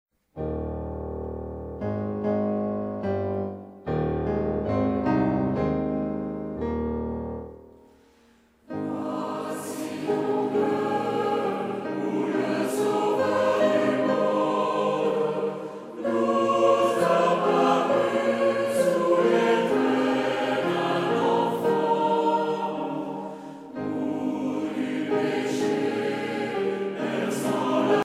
Genre strophique
Chants traditionnels
Pièce musicale éditée